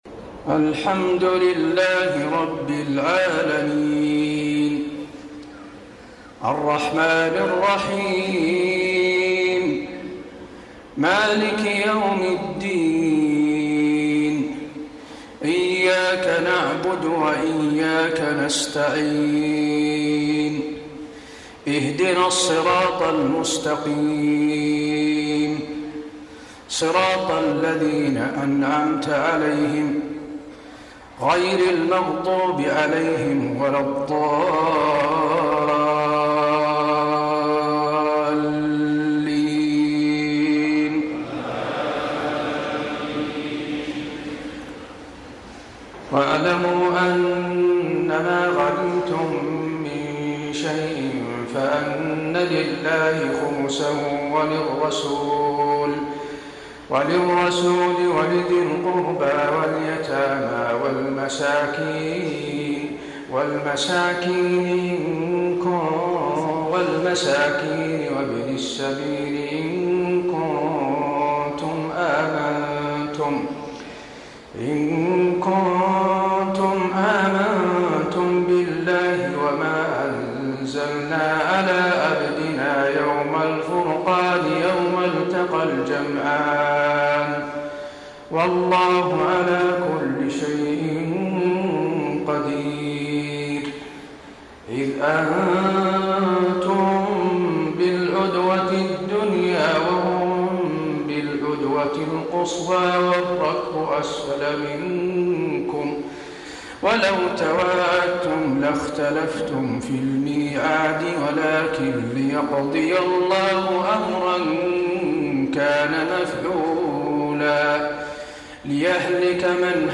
تراويح ليلة 30 رمضان 1433هـ من سورتي الأنفال (41-75) و التوبة (1-33) Taraweeh 30 st night Ramadan 1433H from Surah Al-Anfal and At-Tawba > تراويح الحرم النبوي عام 1433 🕌 > التراويح - تلاوات الحرمين